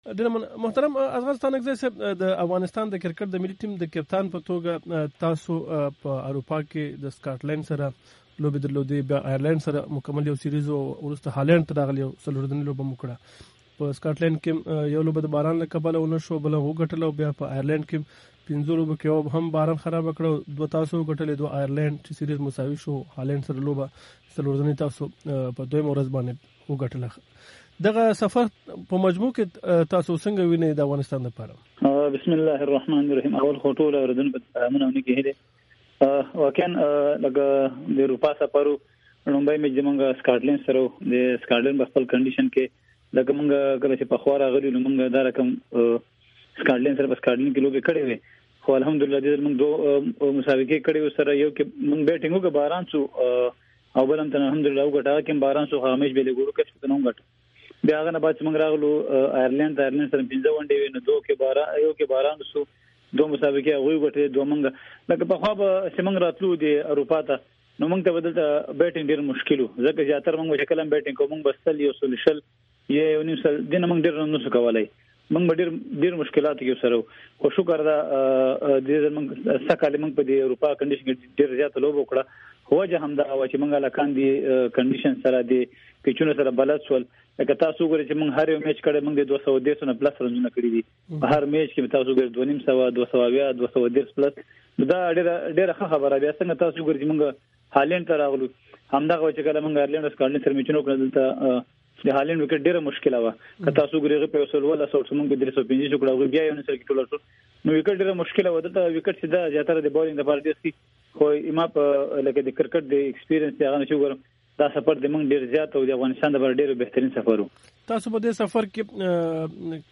د افغانستان د کرکټ د ملي ټیم کپتان اصغر ستانکزي سره مرکه